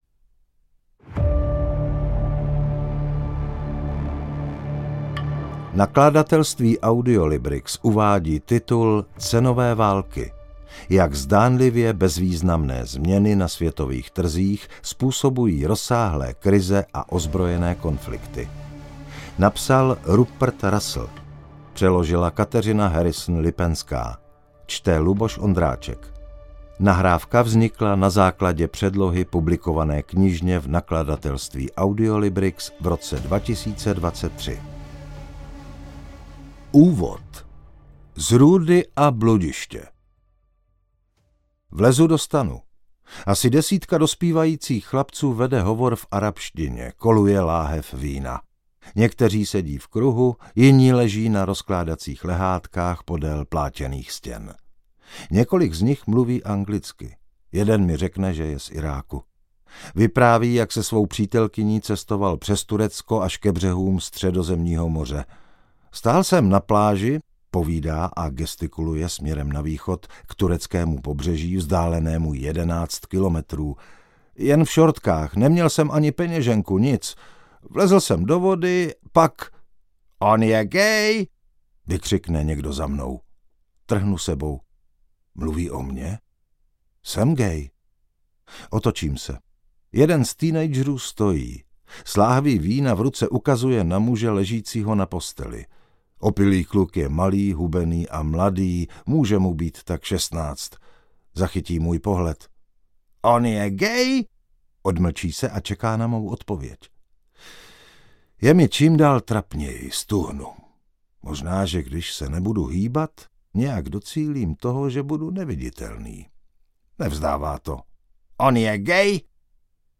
Cenové války audiokniha
Ukázka z knihy